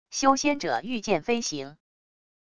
修仙者御剑飞行wav音频